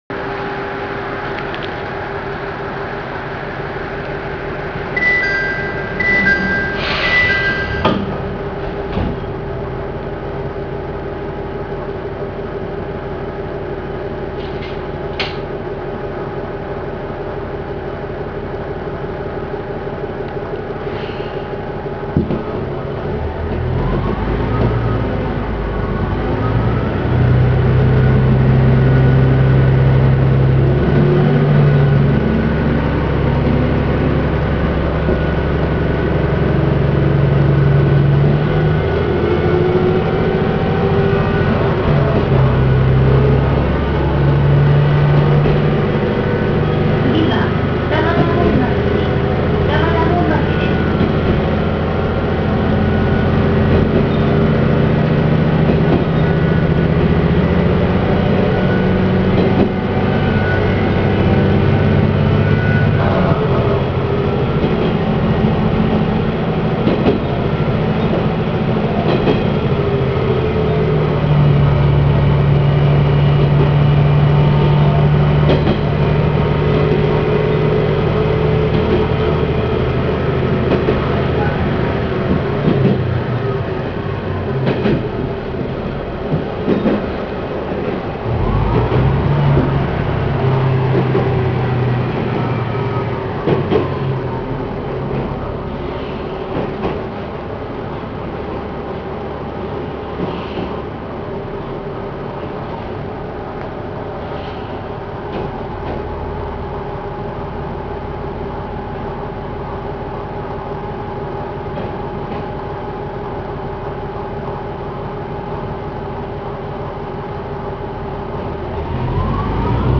〜車両の音〜
・TH2100型走行音
【天竜浜名湖線】西鹿島→二俣本町（3分34秒：1.13MB）
途中で鉄橋を渡るため、かなり減速しています。音自体はごく普通のディーゼルカーの音。加速さえ終われば走行音は軽やかになります。